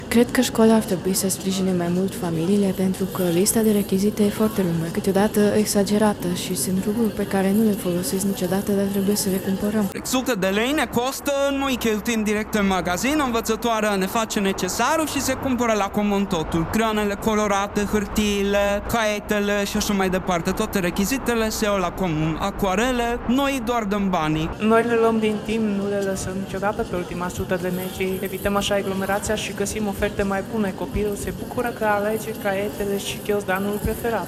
Părinții spun că efortul este mare, mai ales pentru cei care au mai mulți copii.